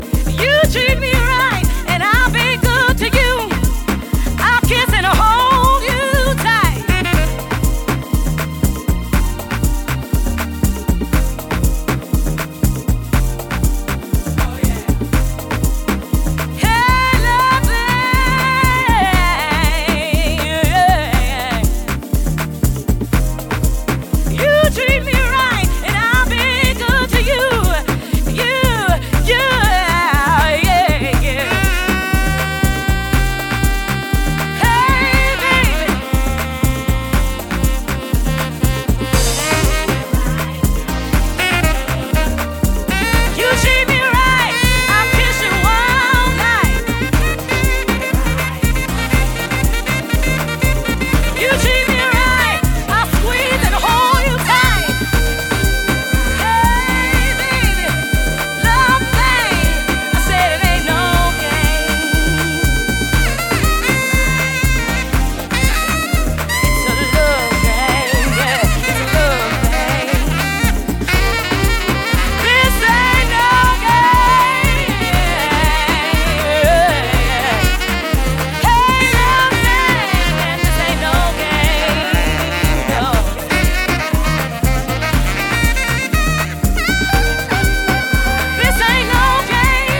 ジャンル(スタイル) DEEP HOUSE / DISCO